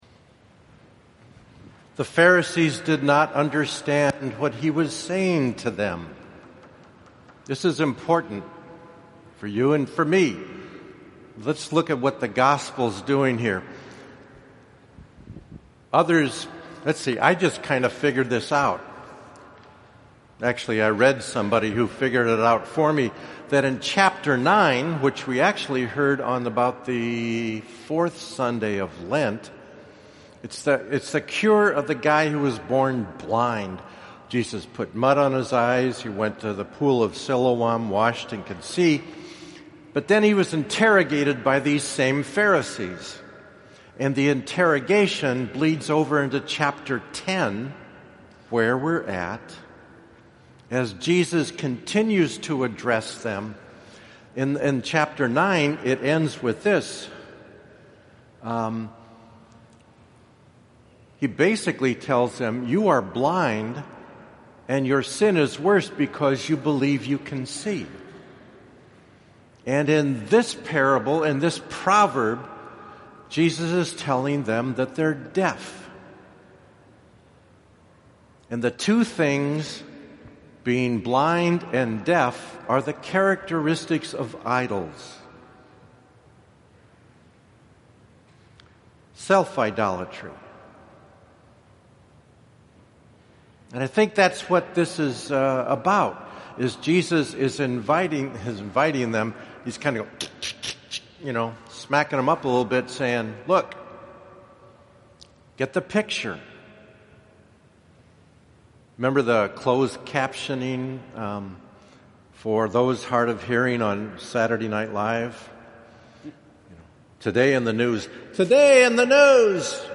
4th of Easter – Audio homily (finally)